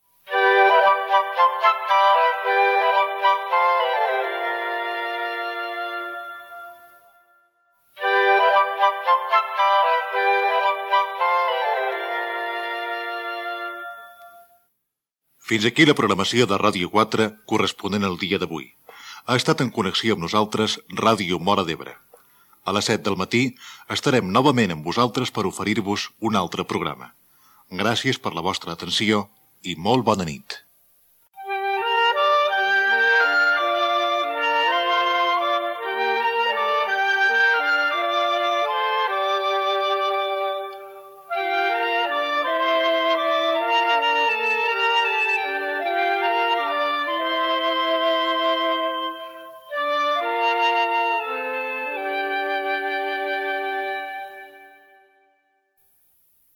Sintonia de l'emissora i tancament de l'emissió feta conjuntament amb Ràdio Móra d'Ebre
FM